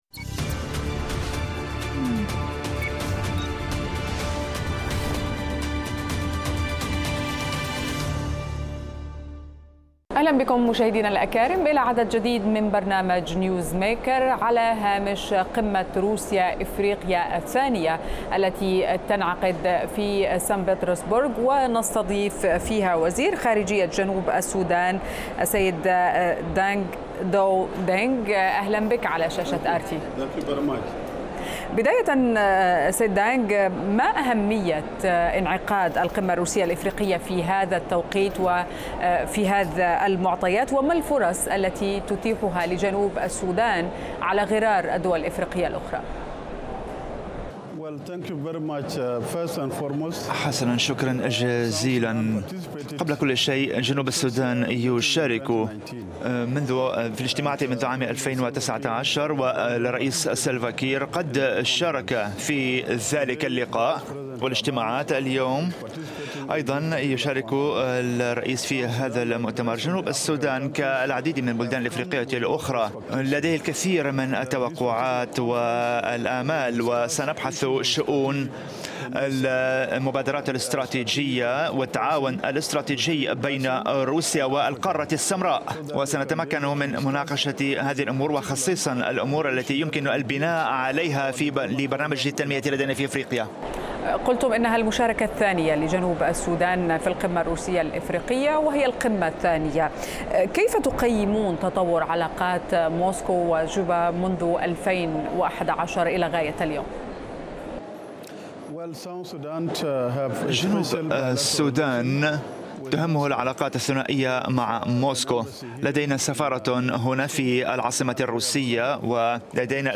تعاون لتمكين دور روسيا بإفريقيا ضيف حلقة اليوم من برنامج “نيوزميكر” وزير خارجية جنوب السودان دينق داو.